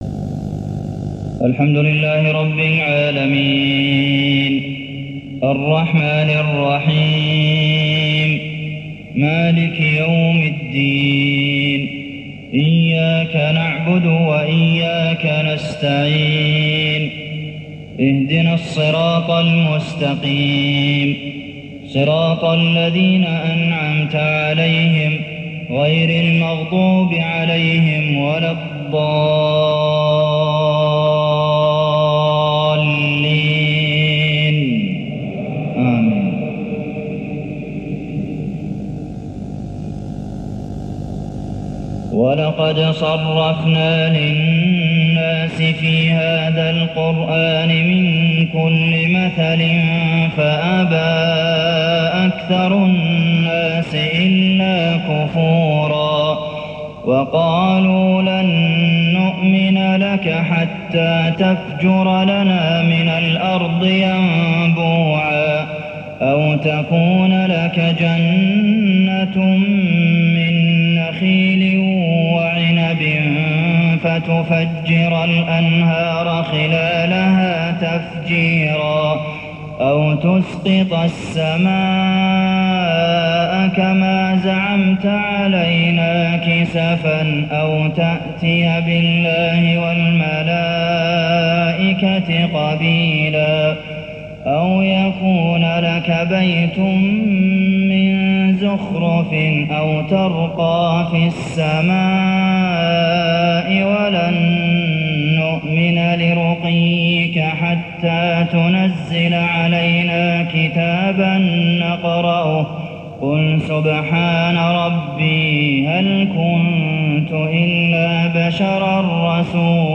صلاة المغرب 1420هـ من سورة الإسراء 89-99 > 1420 🕌 > الفروض - تلاوات الحرمين